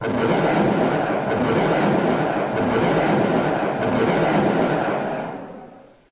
描述：捣鼓捣鼓敲打敲打
Tag: 敲打 敲打